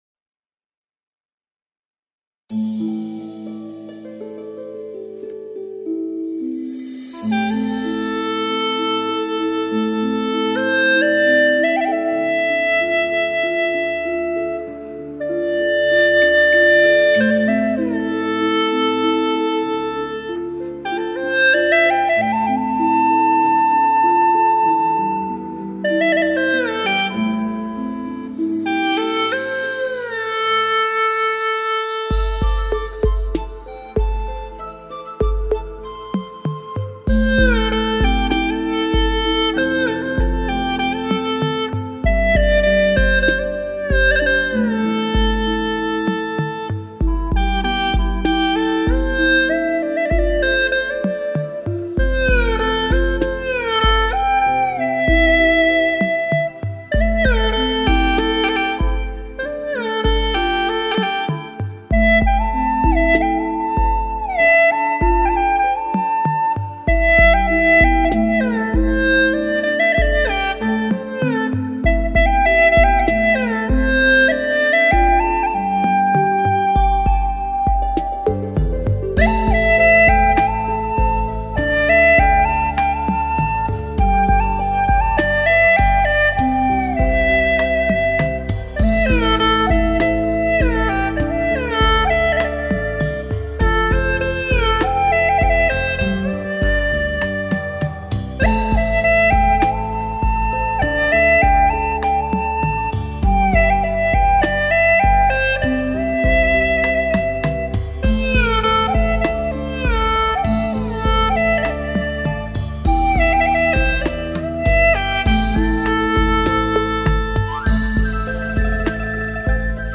调式 : C 曲类 : 独奏
【C调】 我要评论